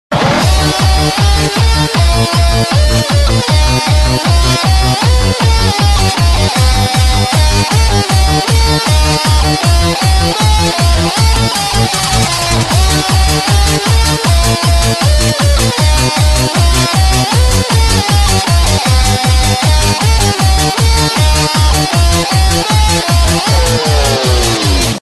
分类: DJ铃声
布鲁斯灵魂 DJ